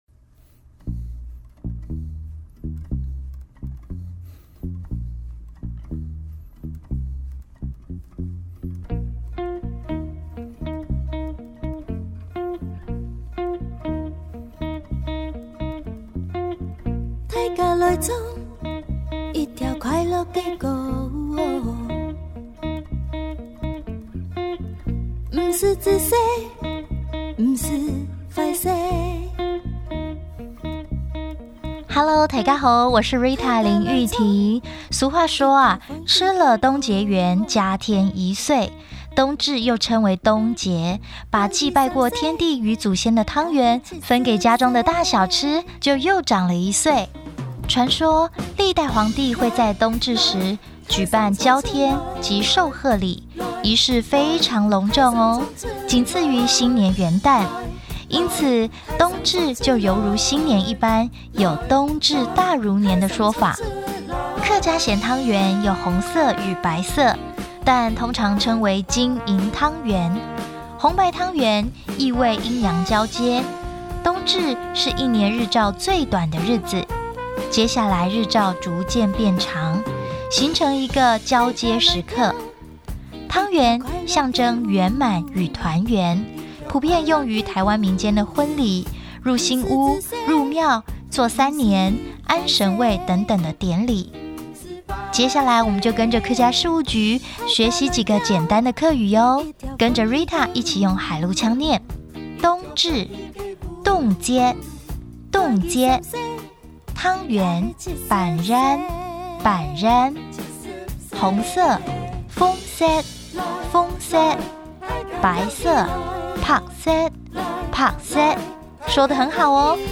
12月冬節(海陸腔) | 新北市客家文化典藏資料庫